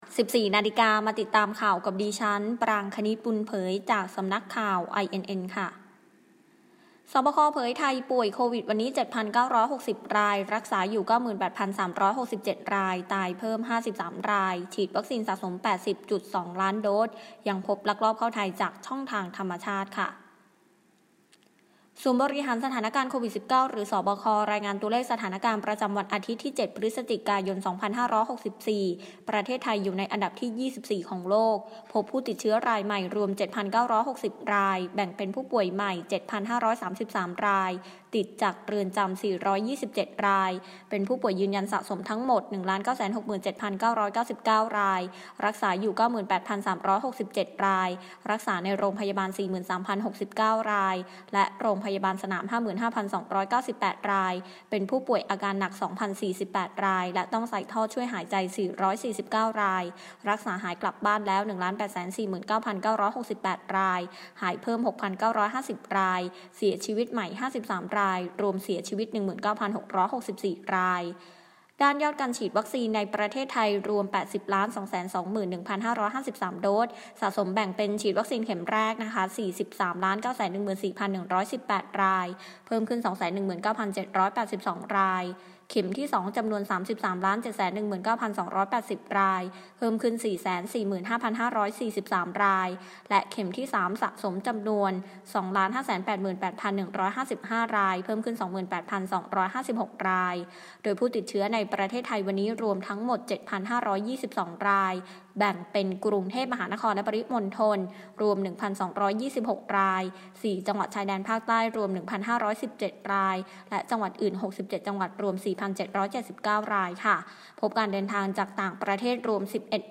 คลิปข่าวต้นชั่วโมง
ข่าวต้นชั่วโมง 14.00 น.